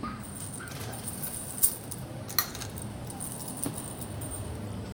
quiet-morning-ambiance-wi-qeveq4sw.wav